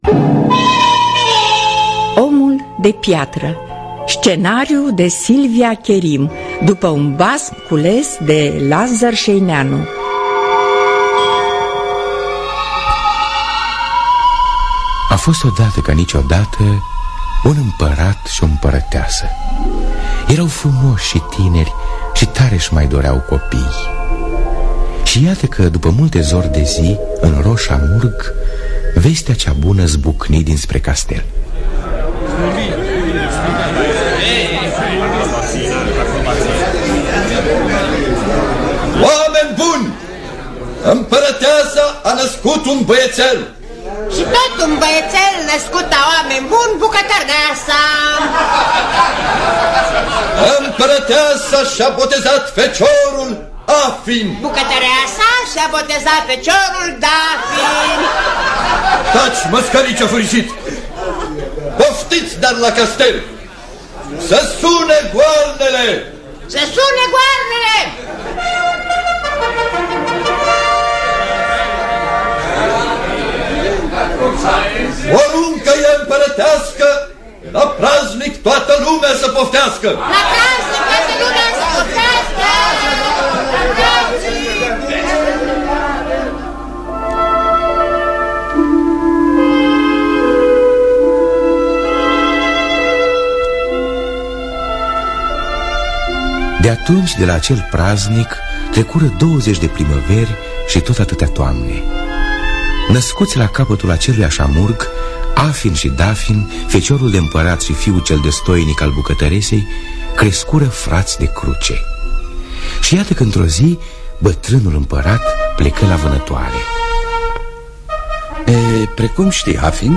Omul de piatră de Lazăr Șăineanu – Teatru Radiofonic Online